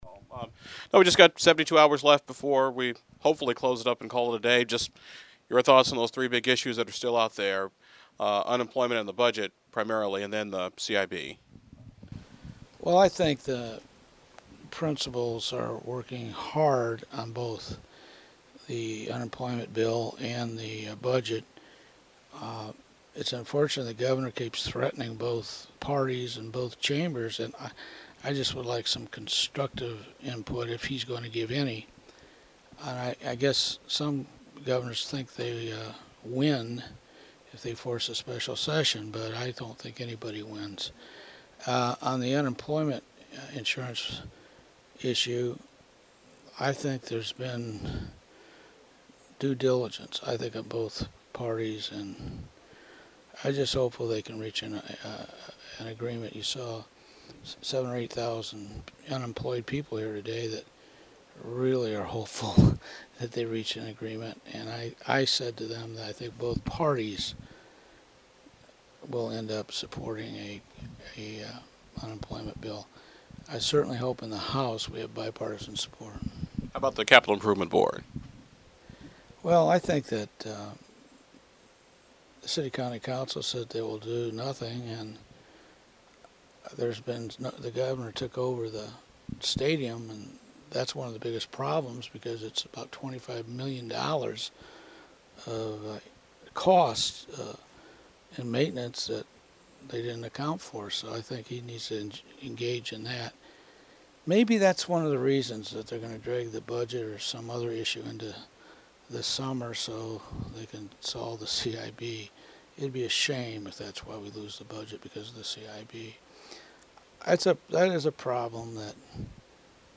I’m at the Statehouse and will try to blog regularly throughout the day on what’s going on here.
House Speaker Pat Bauer